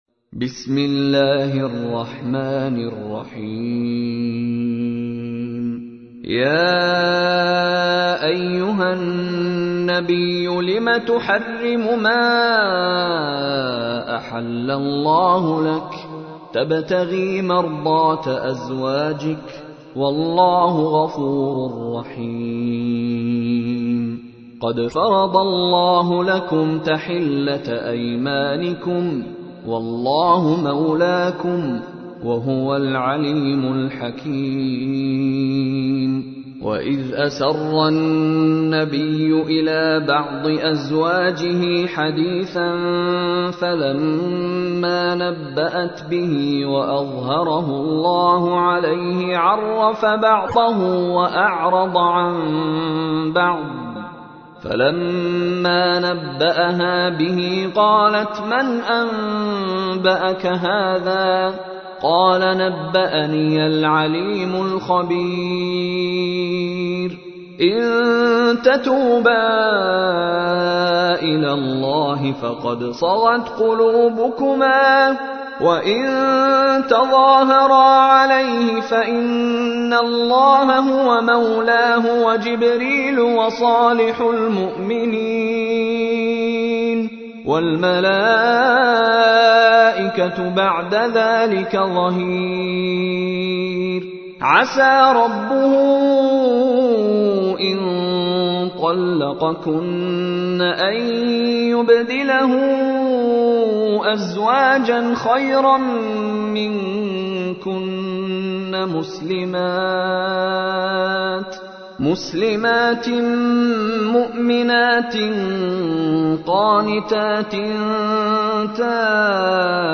تحميل : 66. سورة التحريم / القارئ مشاري راشد العفاسي / القرآن الكريم / موقع يا حسين